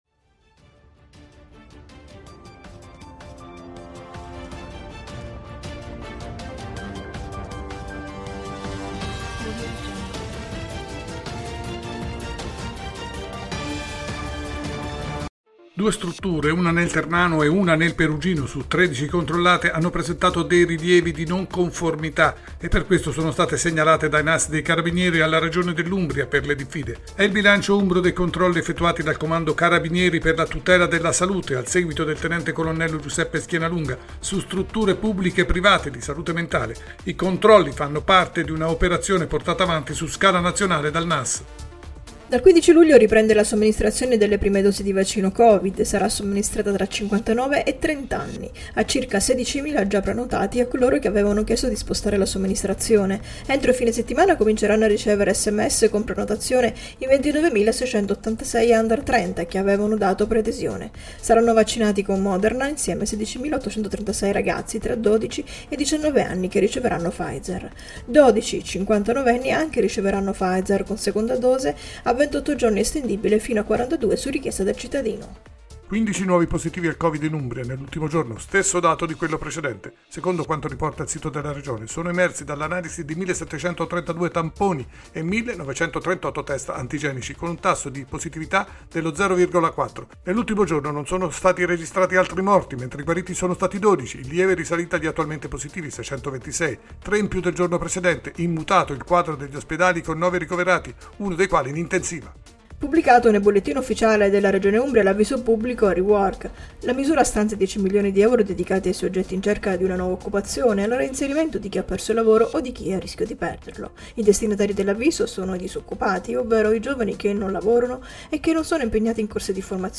Tg dell’Umbria, Radiogiornale della sera, ultime notizie 14.07.2021